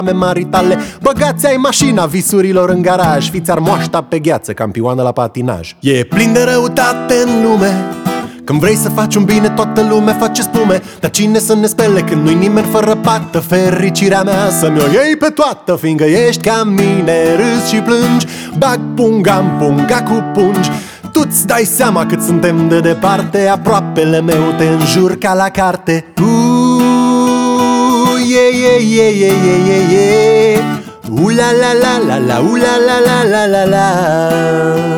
Жанр: Поп / Инди
# Indie Pop